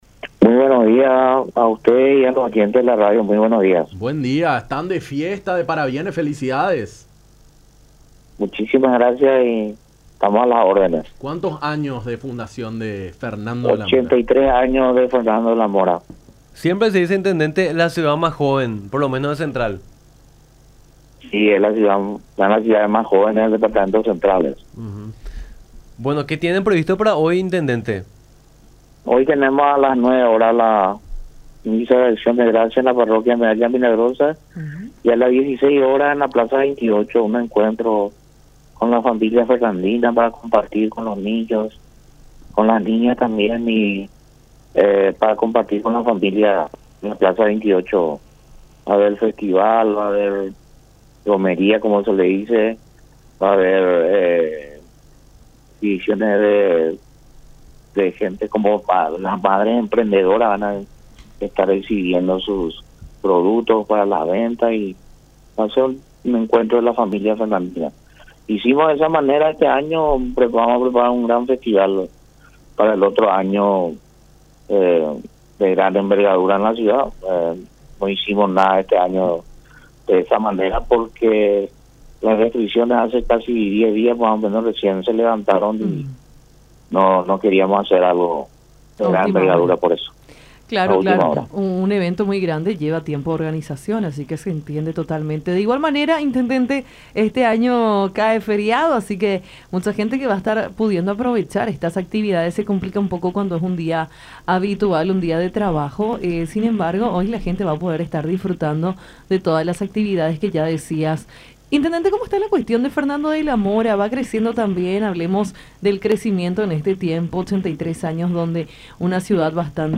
“Vamos a tener diferentes actividades por este nuevo aniversario. Tenemos la misa de Acción de Gracias por la mañana y a la tarde en la Plaza 28 de Septiembre tendremos un encuentro para compartir con las familias, los niños. Habrá un festival”, dijo el intendente de Fernando de la Mora, Alcides Riveros, en charla con Nuestra Mañana por La Unión, quien resaltó que la ciudad que encabeza es la más joven del Departamento Central.